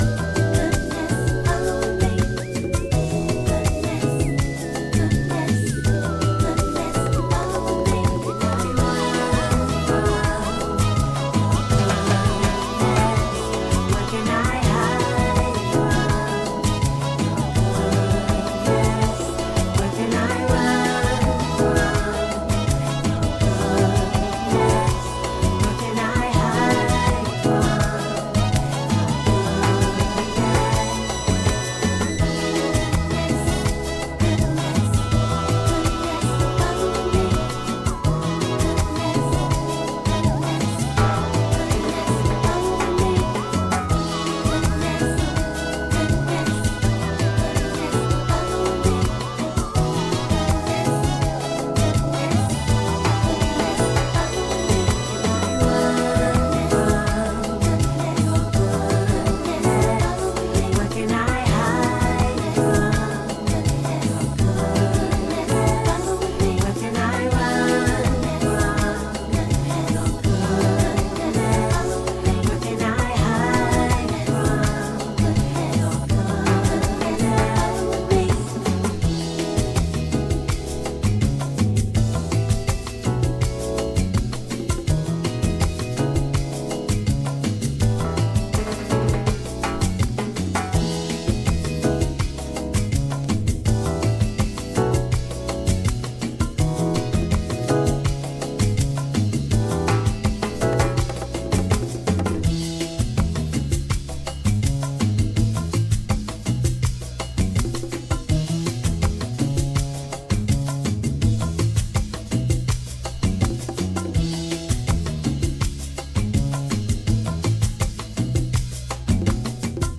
B-2はダウンテンポ